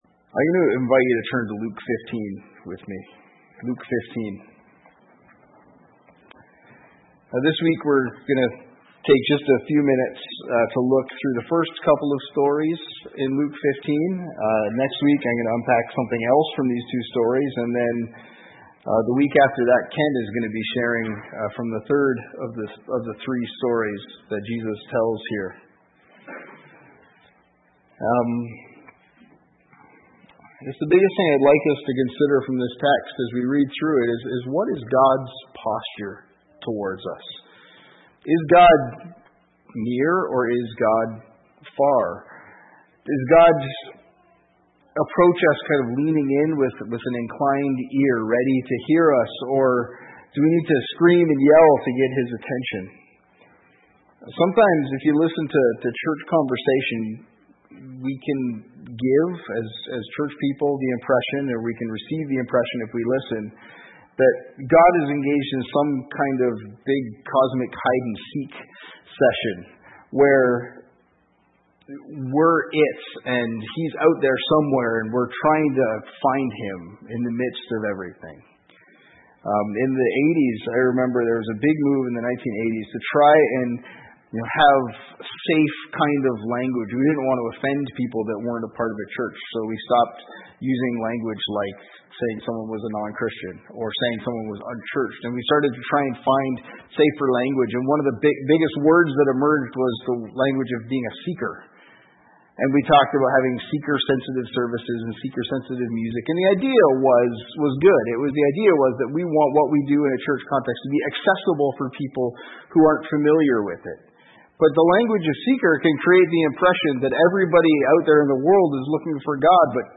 Sermons | Covenant Christian Community Church